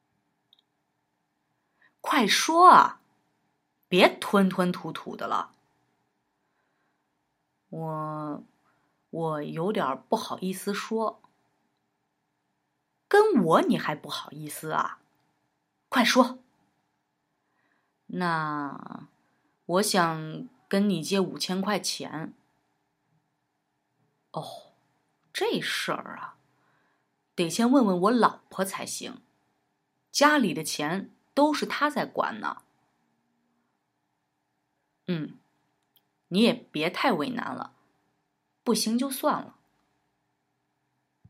Beispieldialog 1  对话